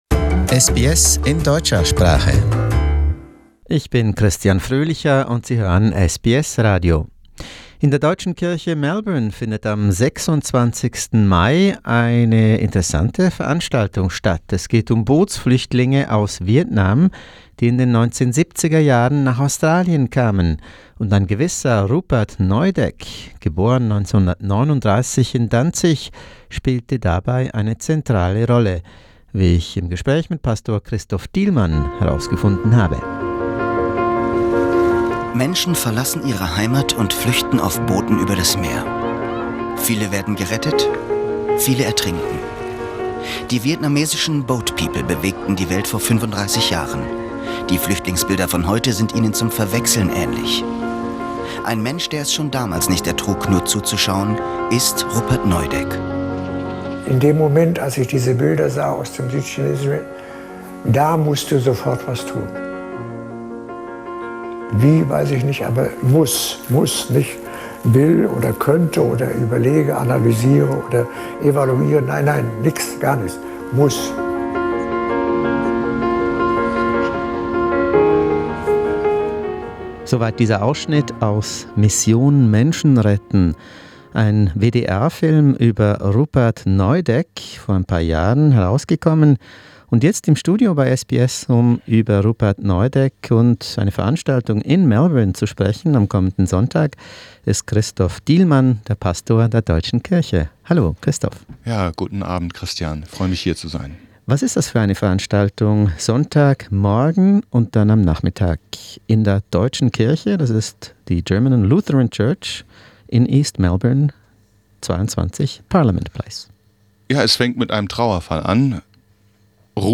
studio talk